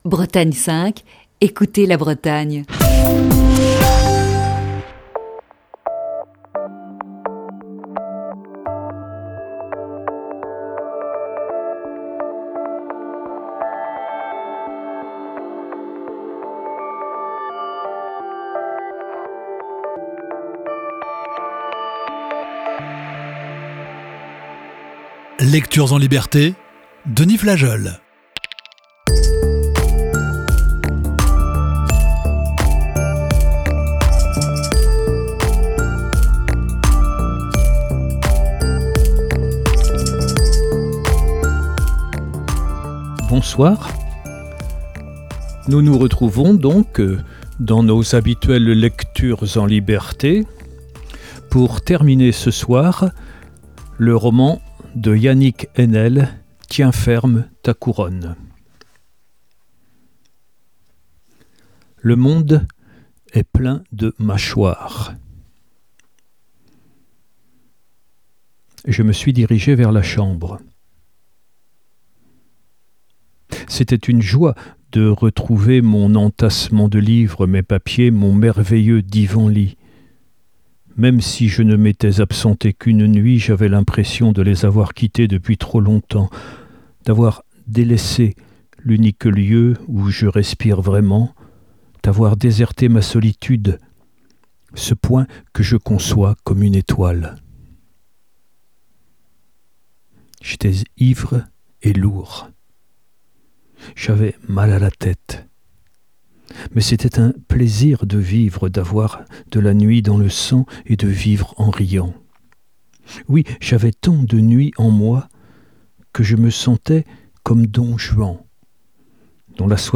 Émission du 27 novembre 2020.